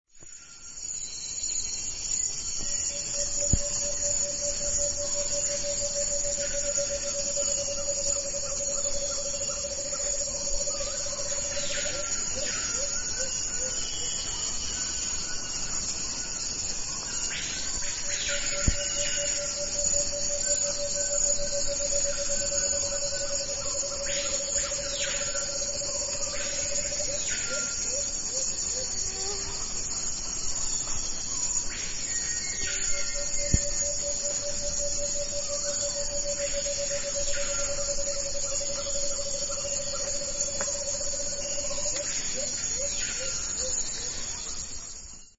With song.
canopysounds2.mp3